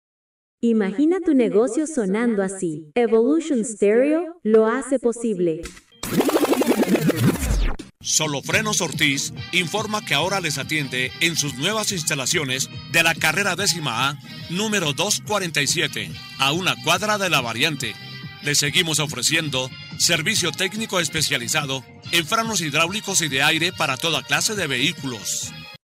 Ejemplo de cuña incluida en el Paquete Básico Demostración ilustrativa del formato y calidad sonora.
CUNA-SOLO-FRENOS-ORTIZ-BASICO-1.mp3